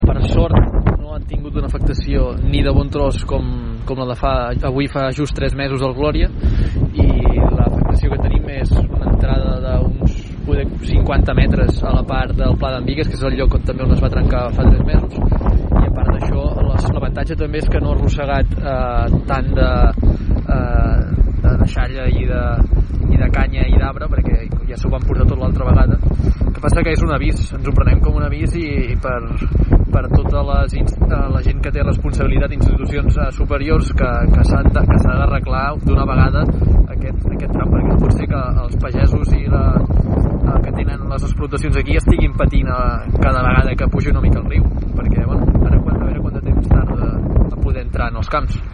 Aleix Freixa, regidor de Medi Ambient de l’Ajuntament de Palafolls, explicava aquest matí des de la zona que l’aigua ha arribat a entrar fins a 50 metres als camps, tot i que l’afectació no és comparable a la del temporal Gloria.